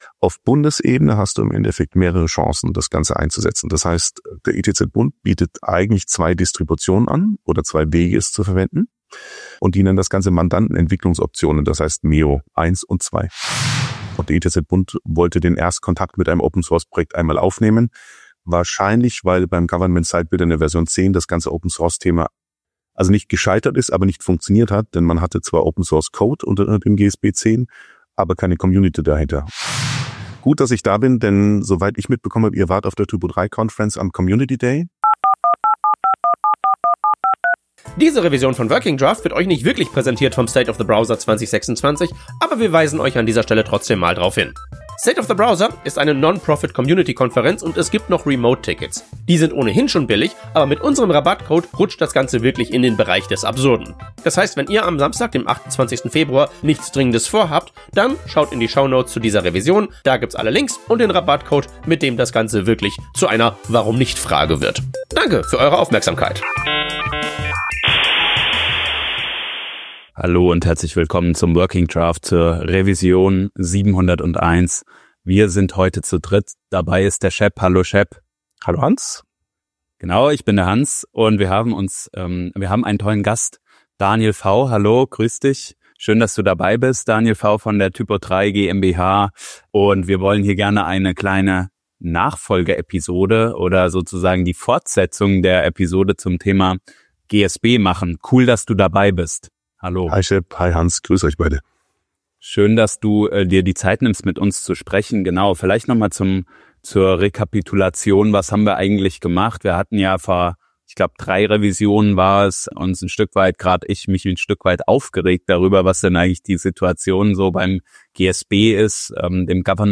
In dieser Folge sprechen wir zu zweit über unsere Eindrücke rund um den Government Site Builder (GSB) – ausgelöst durch unseren Besuch auf der T3CON in Düsseldorf.
Herausgekommen ist stattdessen eine kurze, leicht rantige Bestandsaufnahme darüber, wie schwer es ist, überhaupt belastbare Informationen oder Gesprächspartner:innen zum GSB zu finden.